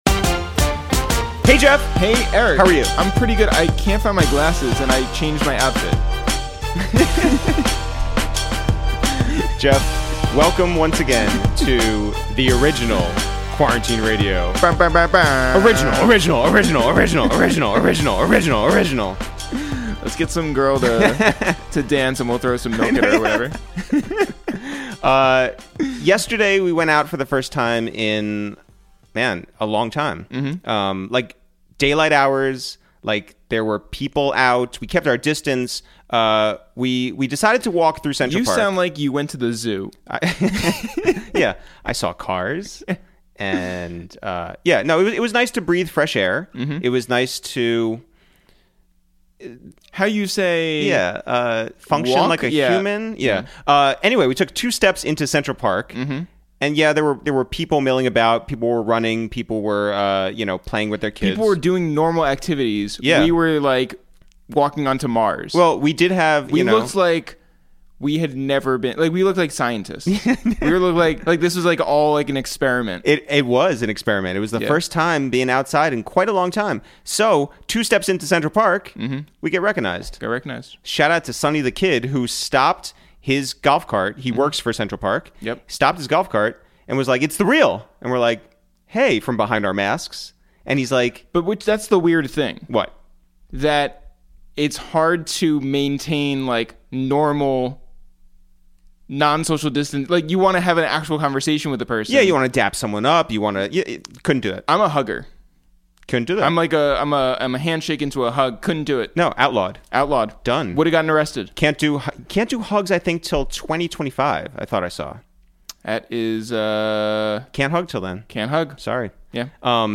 Today on Episode 29 of Quarantine Radio, we make calls from our Upper West Side apartment to check in on producer/rapper/visionary Chuck Inglish, who talks running the Los Angeles Marathon for the first time, watching music documentaries from the 80s, and how one grieves the loss of loved ones during the quarantine.